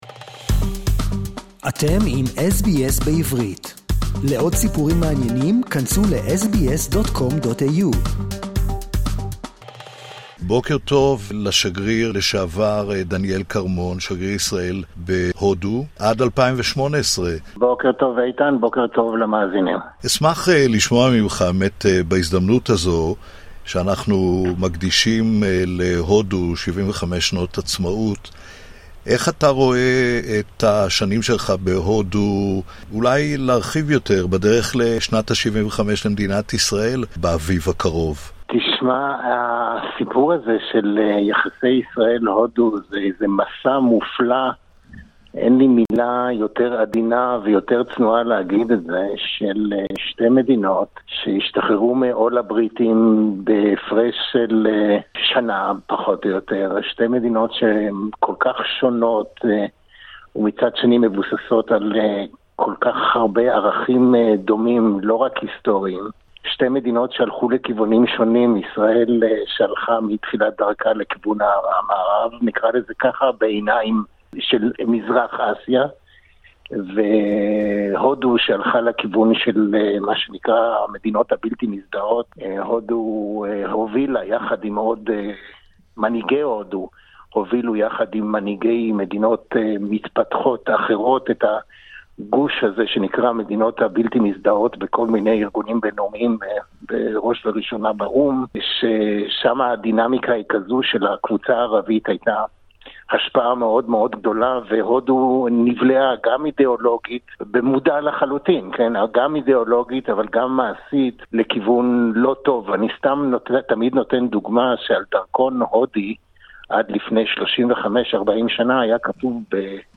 Interview with Ambassador (ret.) Daniel Carmon on India's 75th Anniversary
Former Ambassador of Israel to India, Daniel Carmon